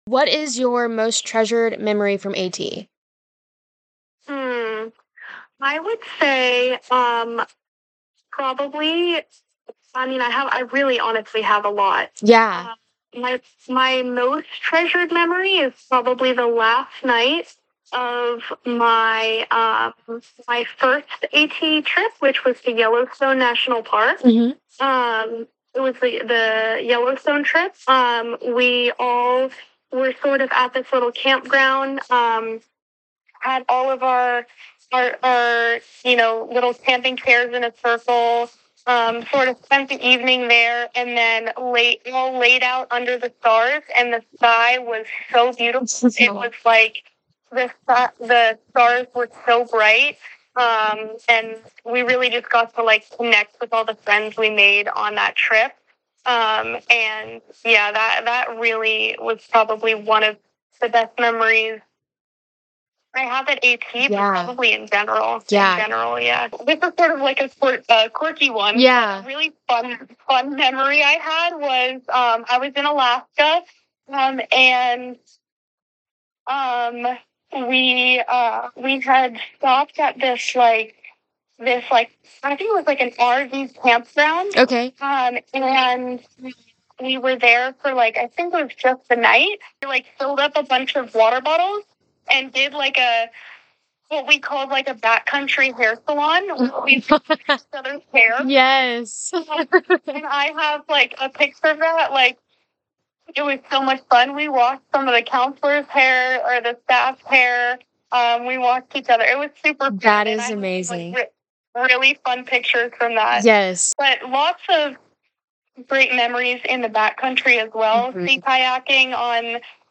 Alumni Interview